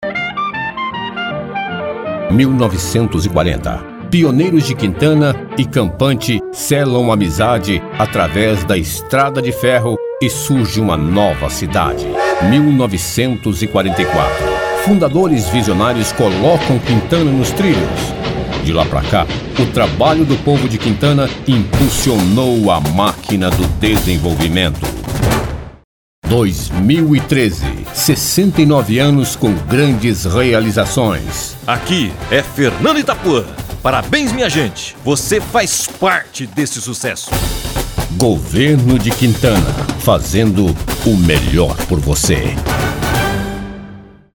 Foram com essas frases que um grupo de amigos de Quintana idealizou e bancou uma campanha publicitária que está sendo veiculada pela Rádio Cidade FM – 91,5.